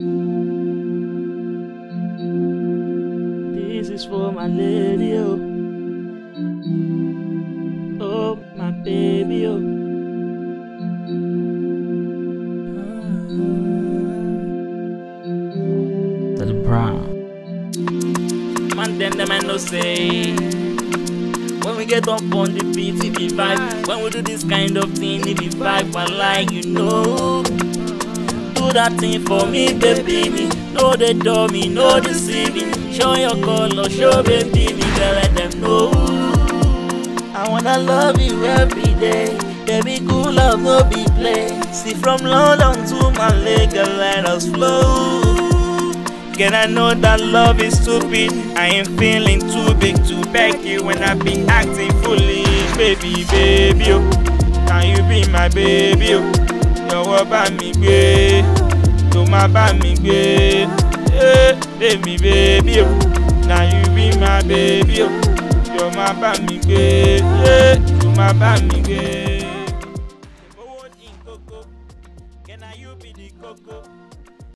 Futuristic afro pop